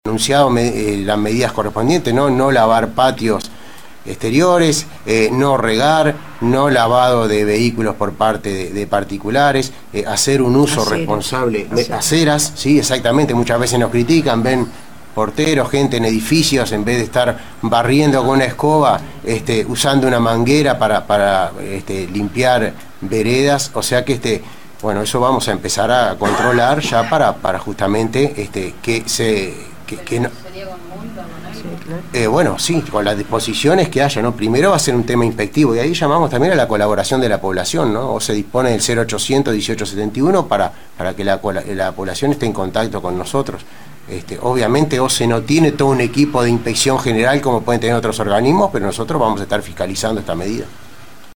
Machado en conferencia